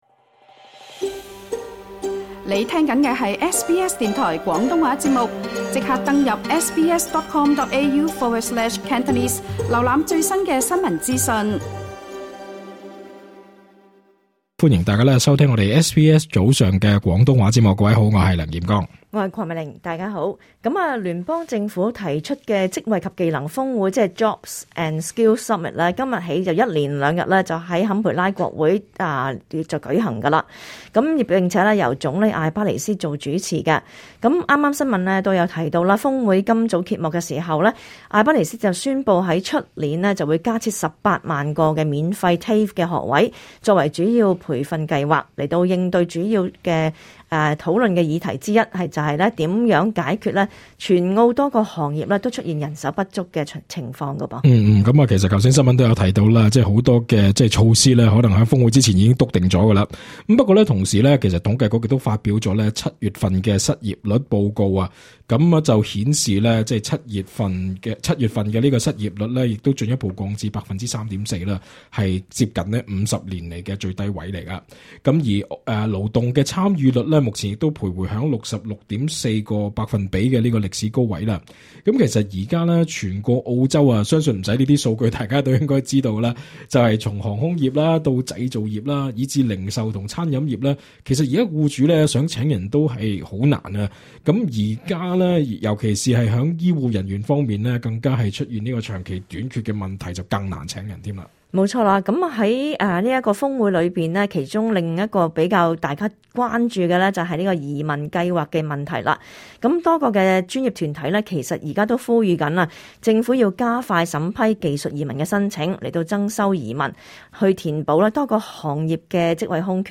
*本節目內嘉賓及聽眾意見並不代表本台立場 READ MORE 澳洲婦女周薪少逾250元 關注組織籲縮窄性別薪酬差距 政府增18萬免費TAFE職訓課程名額 谷本地技術勞工 【罷工24小時】新州護士及助產士再度罷工促增聘人手 瀏覽更多最新時事資訊，請登上 廣東話節目 Facebook 專頁 、 廣東話節目 MeWe 專頁 ，或訂閱 廣東話節目 Telegram 頻道 。